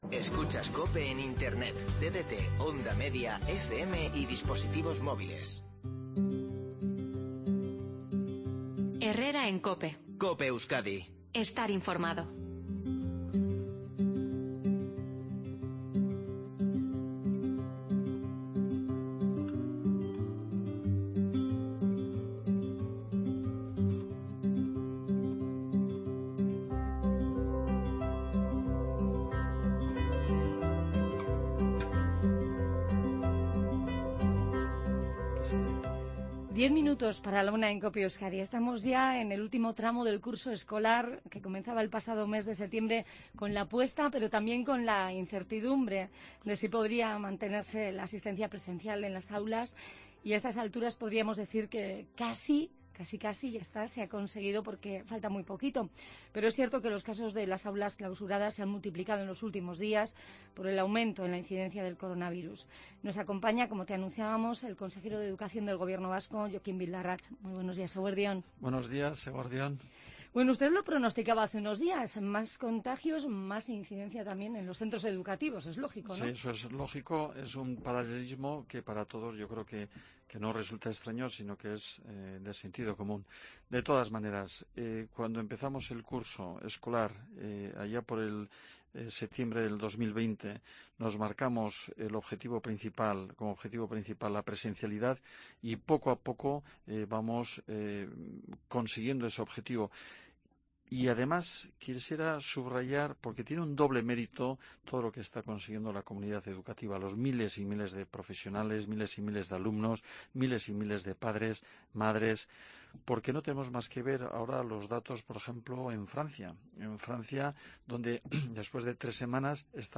En COPE Euskadi hoy, 27 de abril, hemos hablado con el Consejero vasco de Educación, Jokin Bildarratz, para hacer un repaso al curso escolar que comenzaba con la incertidumbre de si se podría mantener de manera presencial en su totalidad.